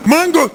hitsound_retro1.wav